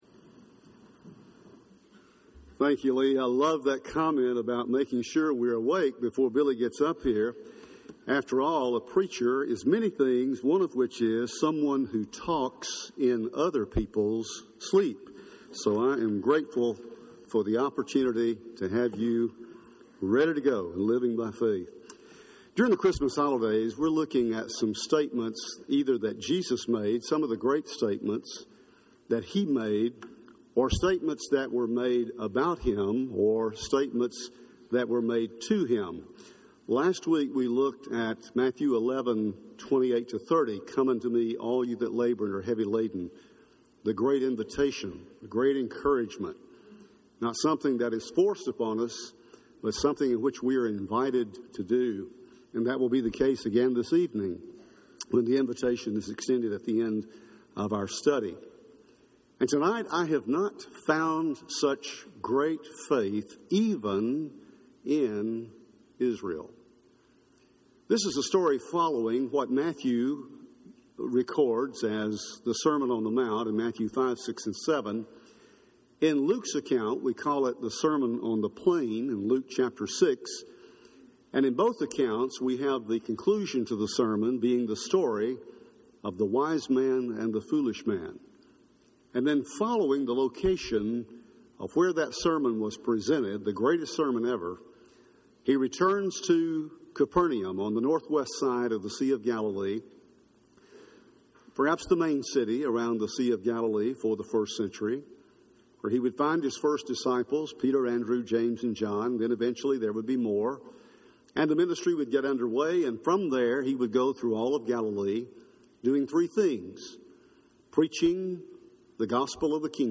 Faith From Unexpected Sources – Henderson, TN Church of Christ